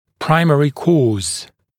[‘praɪmərɪ kɔːz][‘праймэри ко:з]первичная причина, основная причина